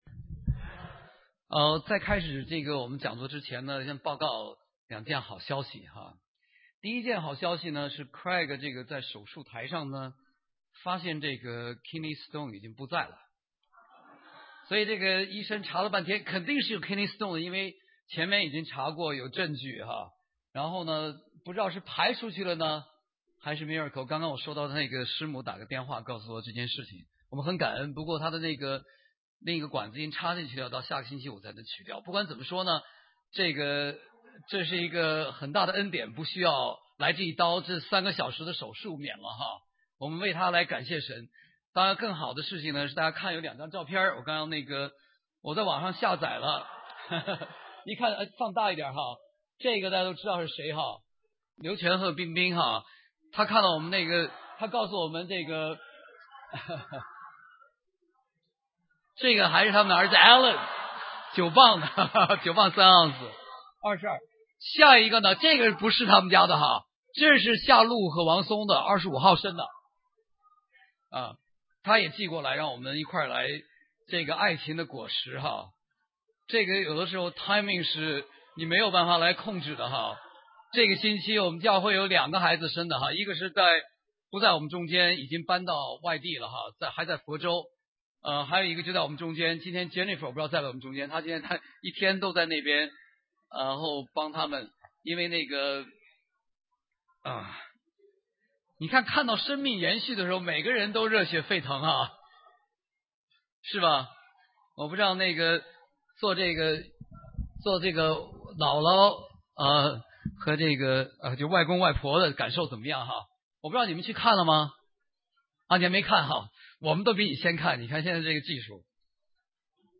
婚姻的美酒--Making Sweet Wine of Marriage in Christ at Chapel(下载)